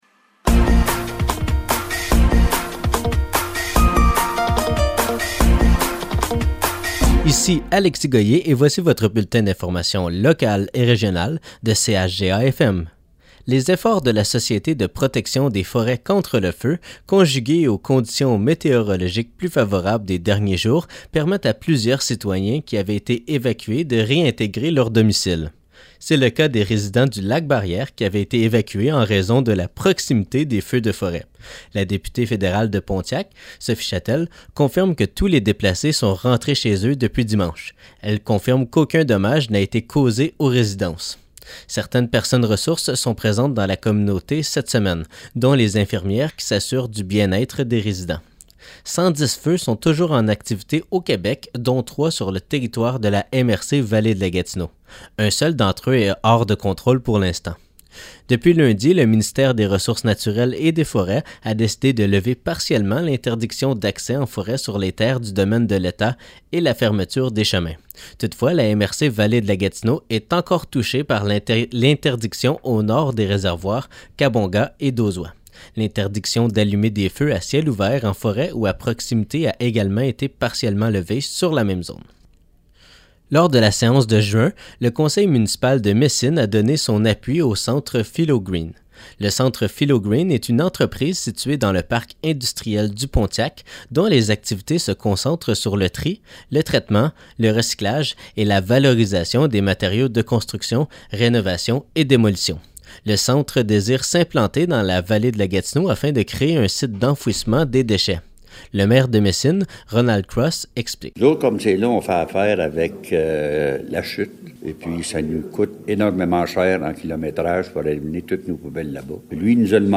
Nouvelles locales - 14 juin 2023 - 12 h